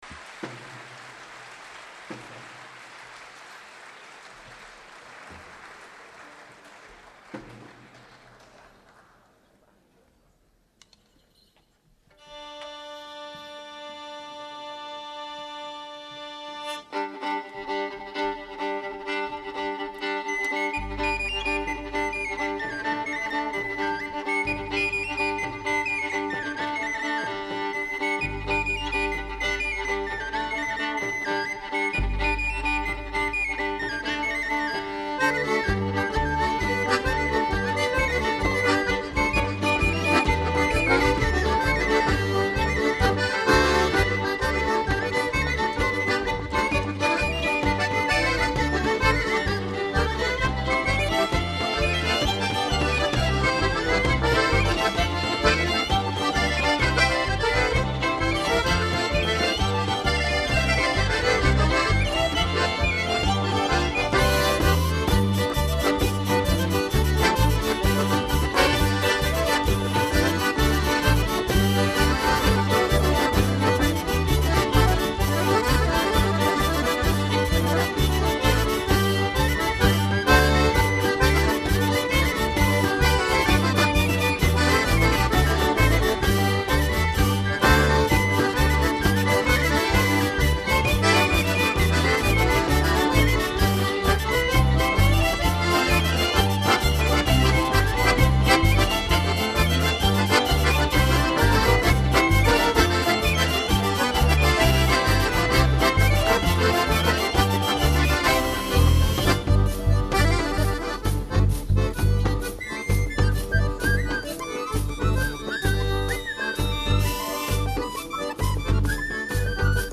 Sbrando (Valli Occitane)                ballo